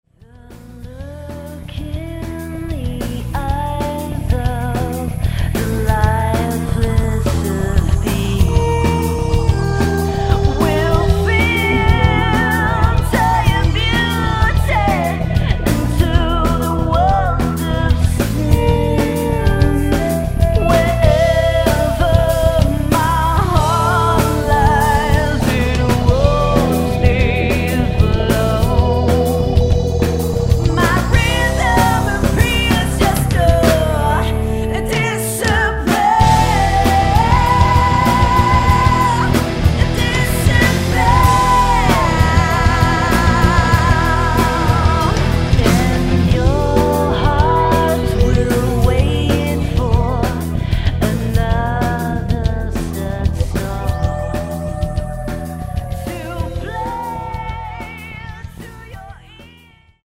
With powerful and emotional melodies
heavy and explosif rifs and rhythms
metal and rock
vocals, rhythm guitar
lead guitar
keyboards
bass